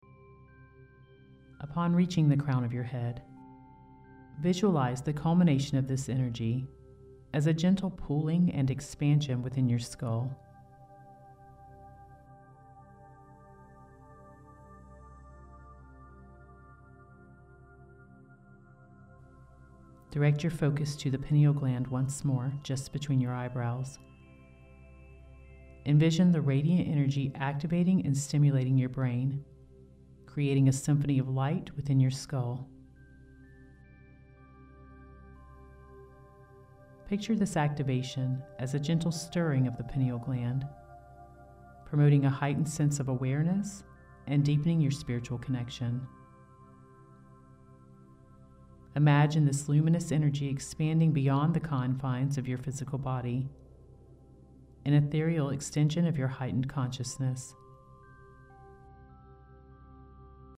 Sacred Secretion Activation – “Activate the Seed” Guided Meditation (Pineal Gland Activation/Kundalini Awakening)
This activation has been combined with an alpha wave musical track to induce feelings of calm, increase creativity and enhance your ability to absorb new information.